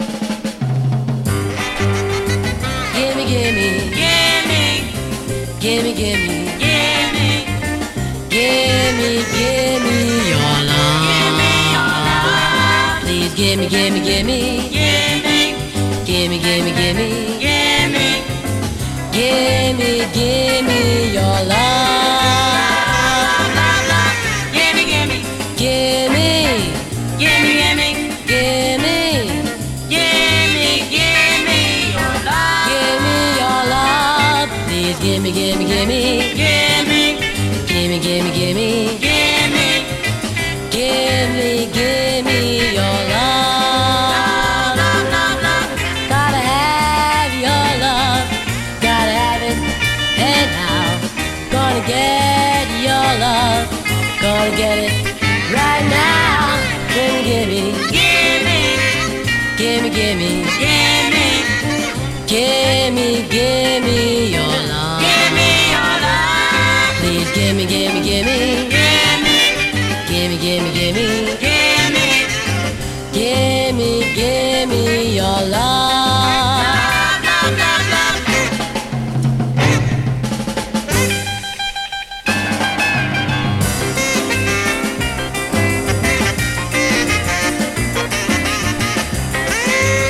EASY LISTENING / EASY LISTENING / 60'S BEAT
ロッキンなギターとドリーミィなストリングス・オーケストラのポップな融合！